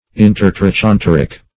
Search Result for " intertrochanteric" : The Collaborative International Dictionary of English v.0.48: Intertrochanteric \In`ter*tro`chan*ter"ic\, a. (Anat.)